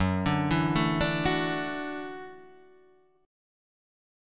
Gbm7#5 chord